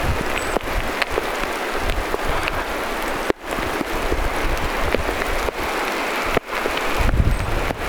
vikiseviä (huomio-) ääniä.
on samanlaista kuin äänitteellä.
amerikan tilhen huomioääniä
ihan_kuin_amerikantilhen_aania.mp3